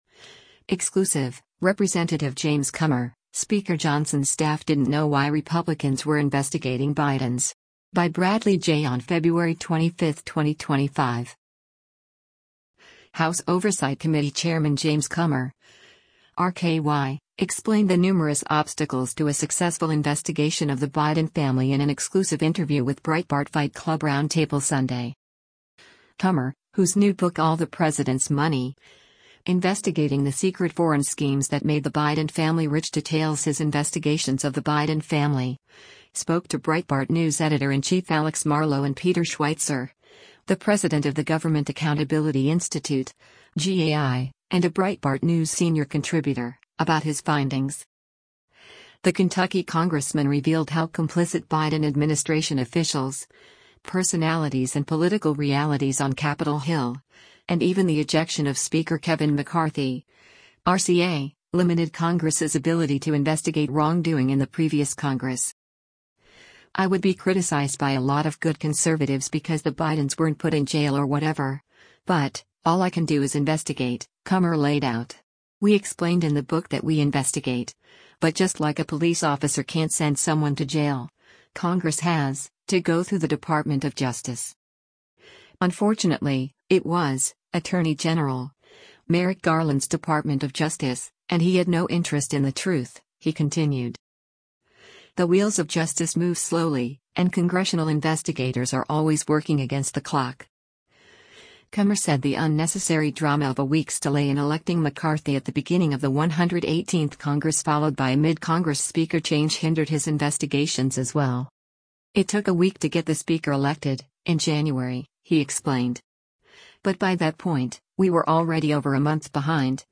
House Oversight Committee Chairman James Comer (R-KY) explained the numerous obstacles to a successful investigation of the Biden family in an exclusive interview with Breitbart Fight Club Roundtable Sunday.